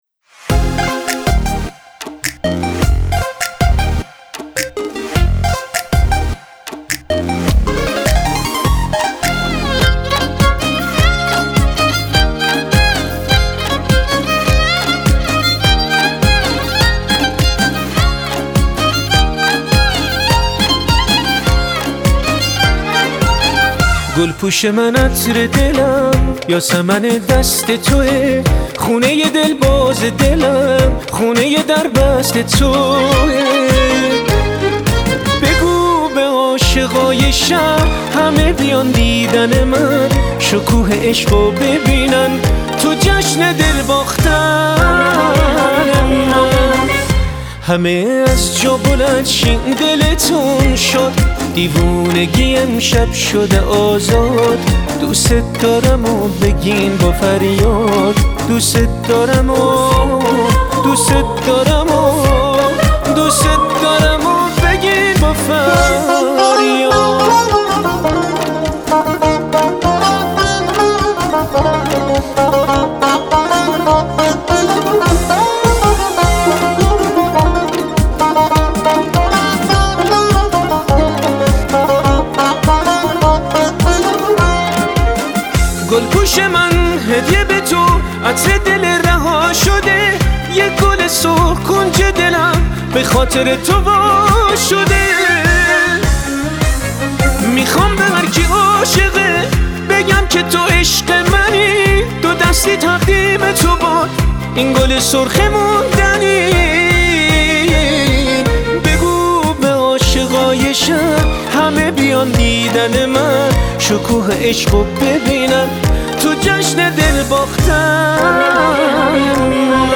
اهنگ بترکون شاد عروسی قدیمی